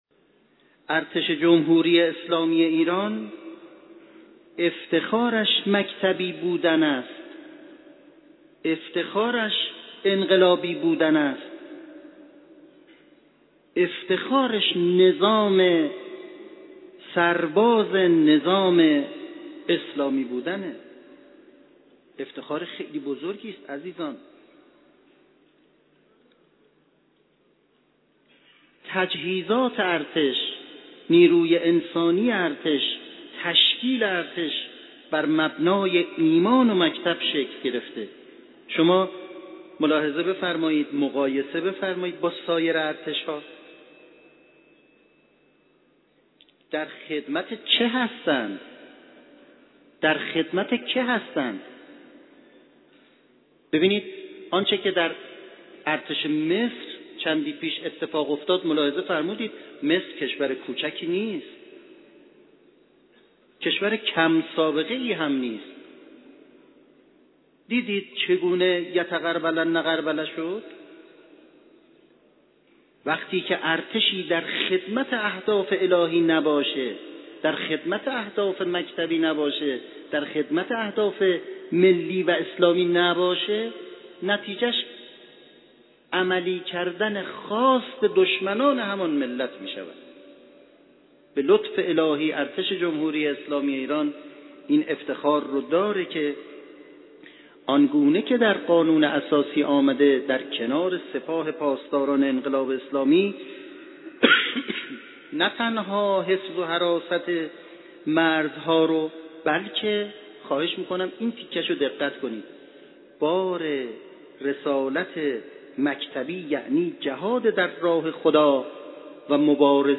به گزارش خبرنگار سیاسی خبرگزاری رسا، امیر سرتیپ رضا خرم طوسی مشاور عالی فرمانده کل ارتش امروز در سخنرانی پیش از خطبه های نماز جمعه قم که در مصلای قدس برگزار شد، با گرامیداشت روز ارتش و یاد و خاطره شهیدان گفت: این ایام یاد آور دلاوری ها و رشادت های ارتش جمهوری اسلامی ایران است.